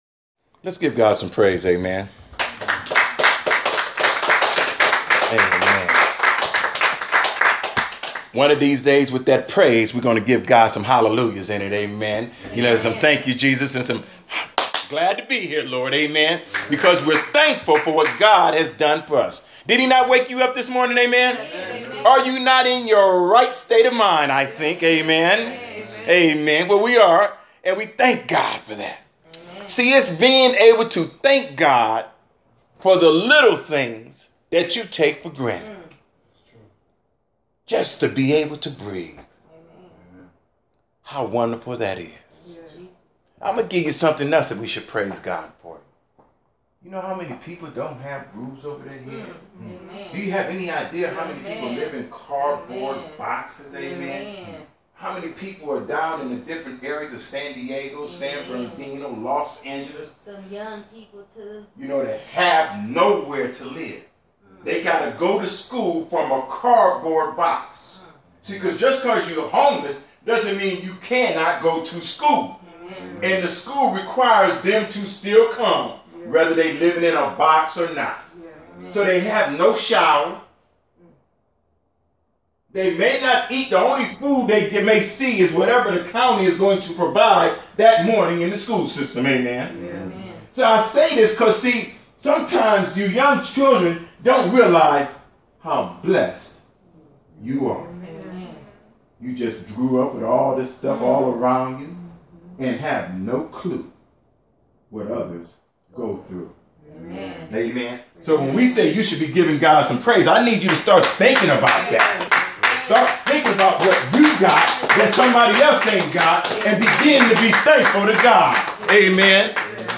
2016 The Importance of Forgiveness Preacher